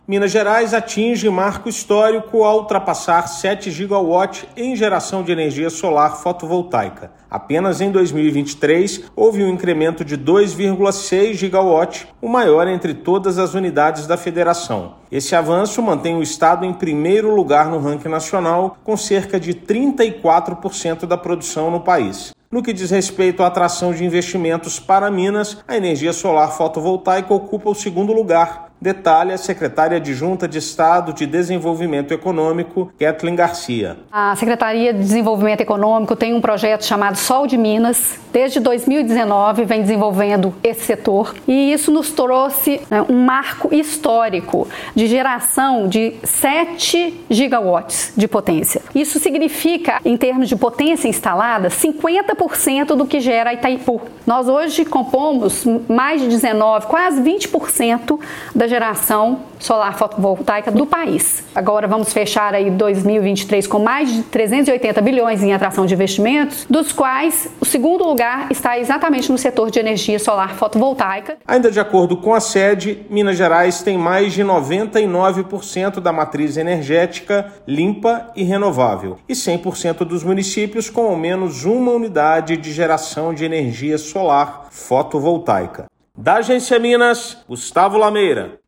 Apoio do Governo, com projetos como o Sol de Minas, contribuiu para incentivar investimentos na produção da energia limpa no estado. Ouça matéria de rádio.